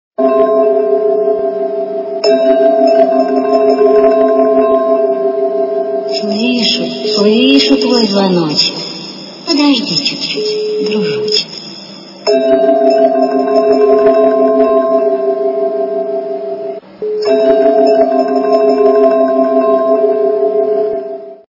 » Звуки » Смешные » Волшебный звонок - Слышу, слышу, твой звоночек. Подожди чуть-чуть дружочек!
При прослушивании Волшебный звонок - Слышу, слышу, твой звоночек. Подожди чуть-чуть дружочек! качество понижено и присутствуют гудки.